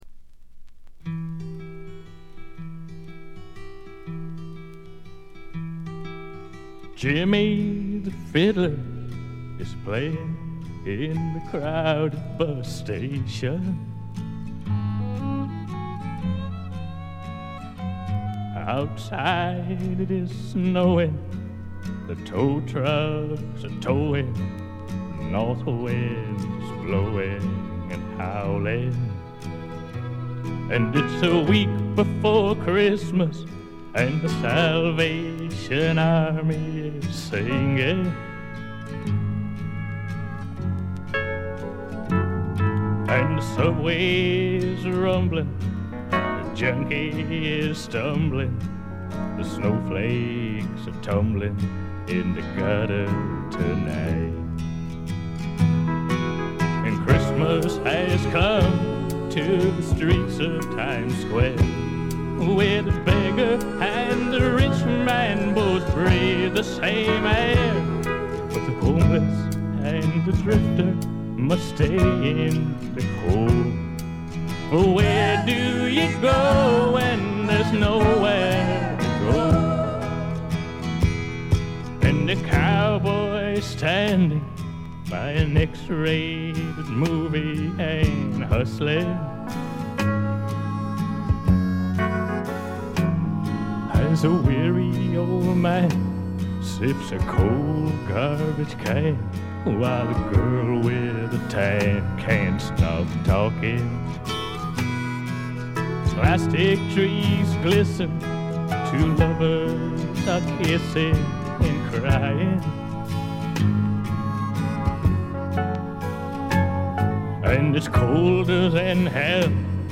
部分試聴ですがごくわずかなノイズ感のみ。
70年代シンガー・ソングライター・ブームが爛熟期を迎え、退廃に向かう寸前に発表されたフォーキーな名作です。
シンガー・ソングライター基本盤。
試聴曲は現品からの取り込み音源です。
Guitar
Piano
Drums
Bass
Background Vocal